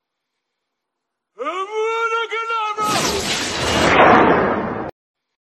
拍打身法
描述：可做出手 拍打 身法
Tag: 武侠 打斗 徒手 身法 动作